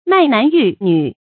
卖男鬻女 mài nán yù nǚ
卖男鬻女发音